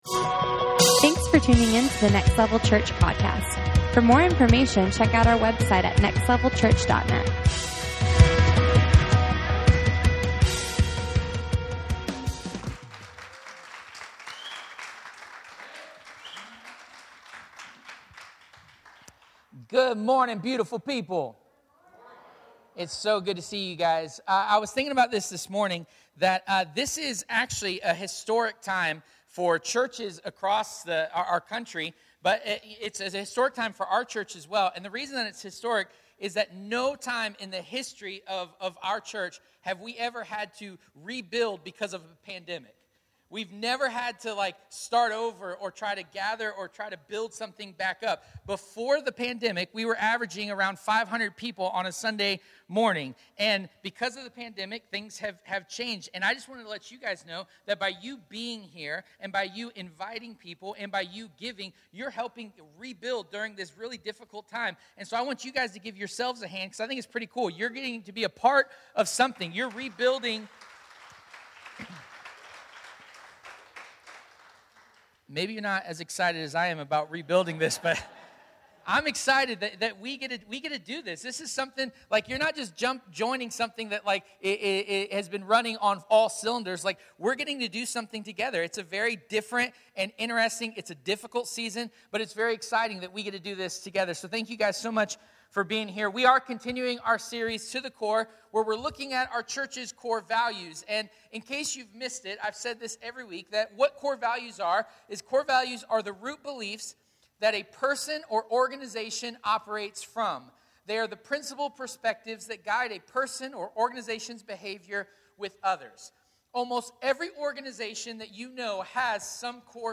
To The Core Service Type: Sunday Morning Watch There is no such thing as a perfect church.